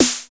SD_SD 909 ai.wav